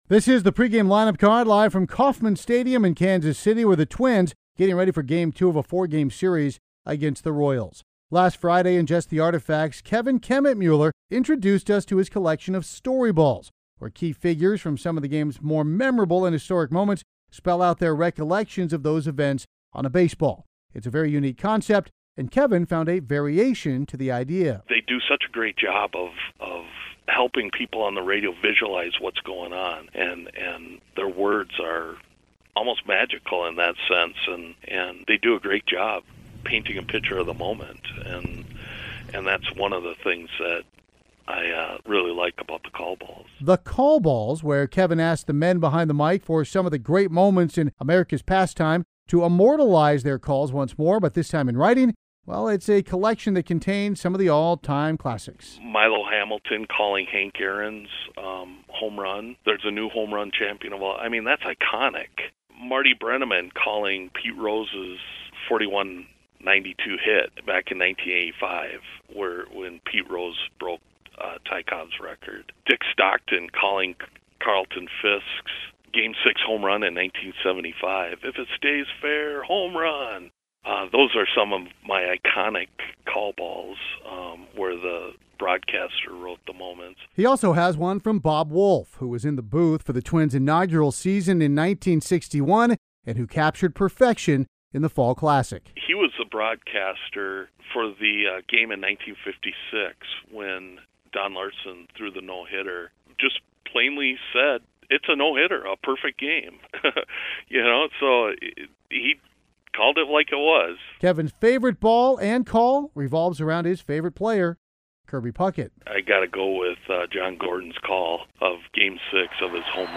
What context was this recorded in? As part of tonight’s Twins pregame show, they played the second part of my interview on my baseball collection.